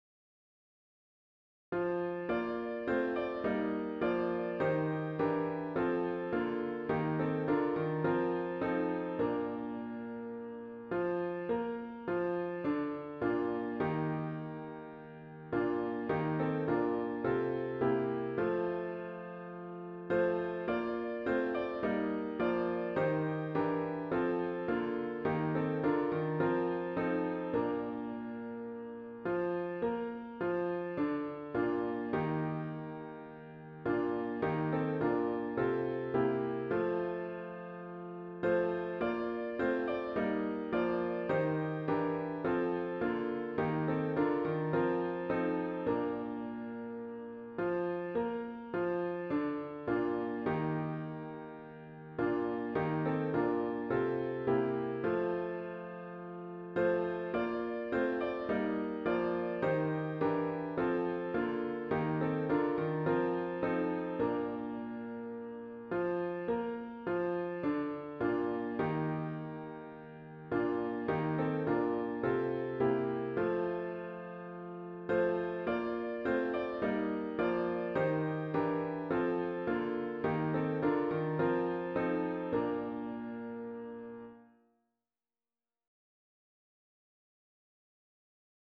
*CLOSING HYMN “Arise, Your Light Is Come!”